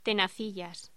Locución: Tenacillas
voz